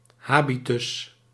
Ääntäminen
RP : IPA : /ˈmænə/ GenAm: IPA : /ˈmænɚ/